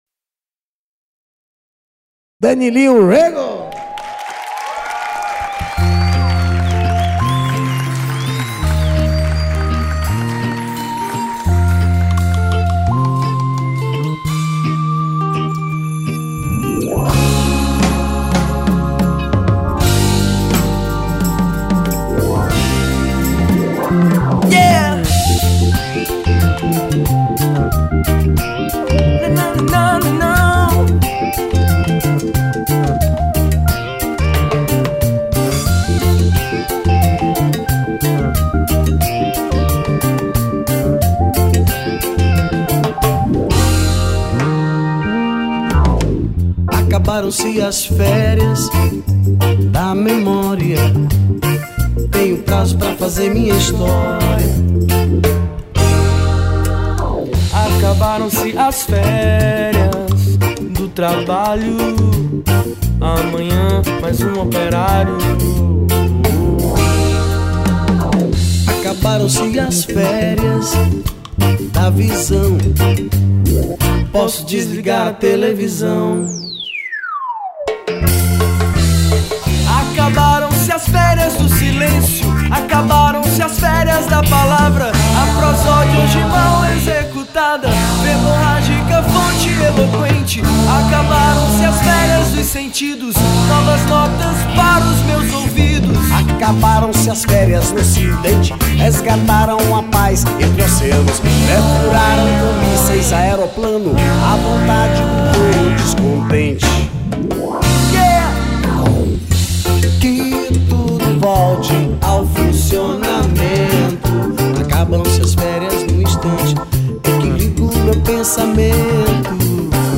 2960   05:23:00   Faixa: 4    Rock Nacional